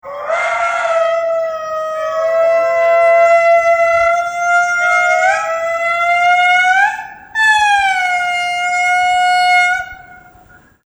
What do lemurs sound like?
When the latter occurs you get wonderful intermodulation products. The sound is a little like that recording of whale song that everyone has."
twoLemurs.mp3